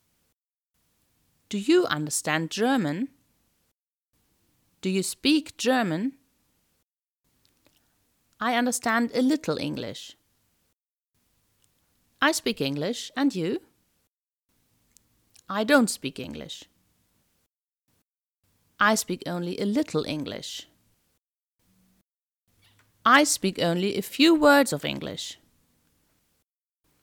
Dies dient dem Erspüren der Sprachmelodie und um herauszufinden, wie viel man schon ohne die Übersetzung versteht.
Hörprobe-A1-reintext.mp3